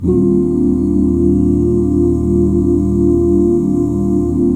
EBMAJ7 OOO-L.wav